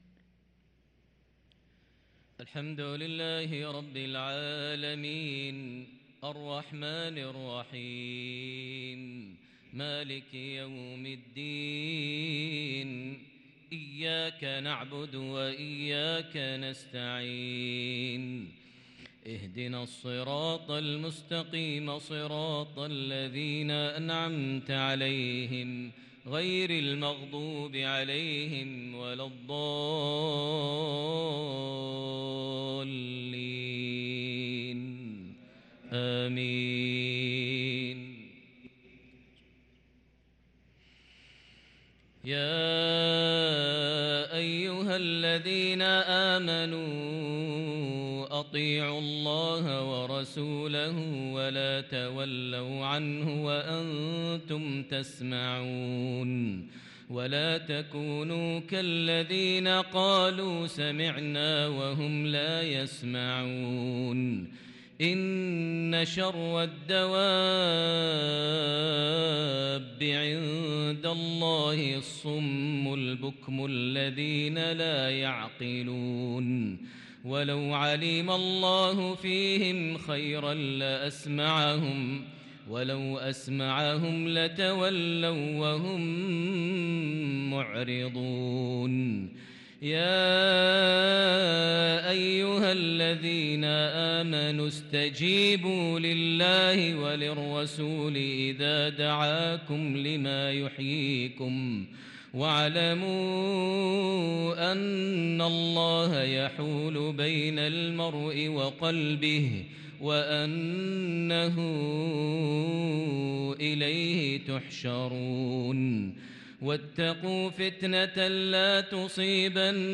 صلاة العشاء للقارئ ماهر المعيقلي 29 ذو الحجة 1443 هـ
تِلَاوَات الْحَرَمَيْن .